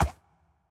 Minecraft Version Minecraft Version 1.21.5 Latest Release | Latest Snapshot 1.21.5 / assets / minecraft / sounds / mob / horse / skeleton / water / soft3.ogg Compare With Compare With Latest Release | Latest Snapshot
soft3.ogg